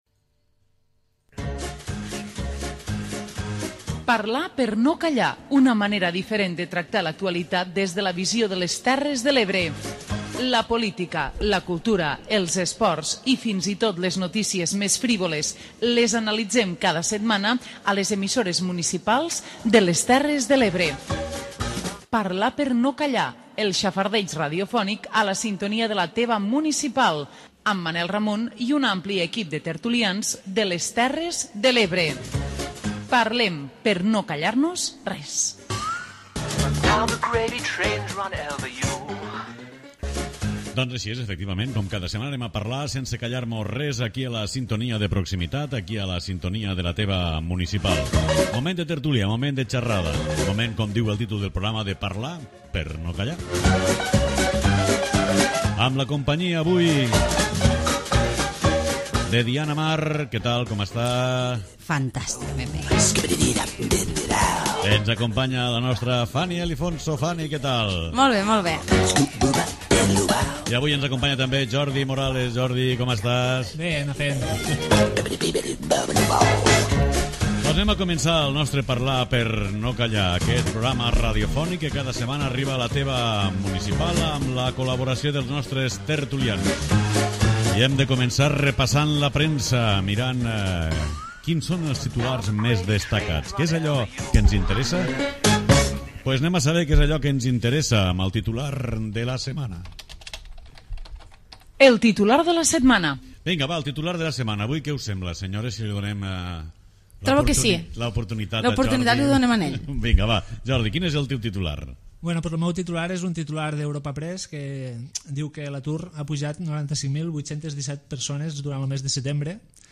Parlar per no Callar, la tertúlia d'àmbit territorial d'EMUTE. 55 minuts de xerrada distesa amb gent que vol opinar. Busquem la foto, el titular i les carabasses de la setmana.